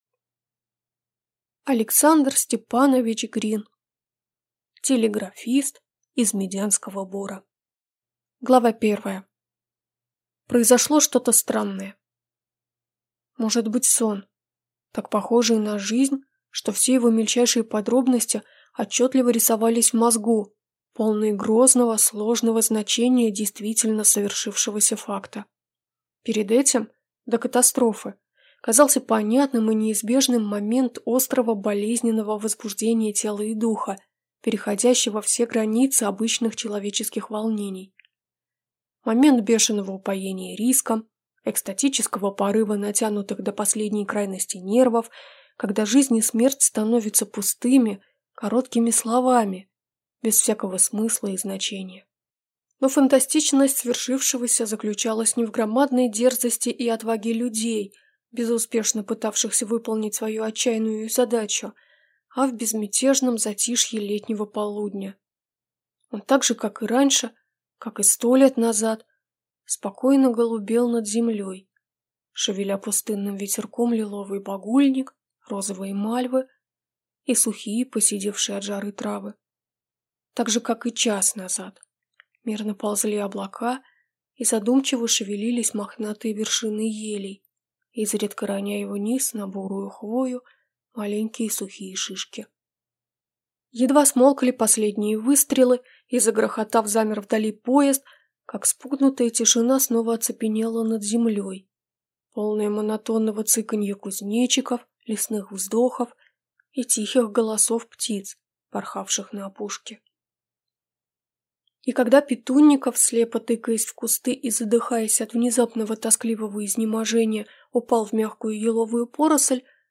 Аудиокнига Телеграфист из Медянского бора | Библиотека аудиокниг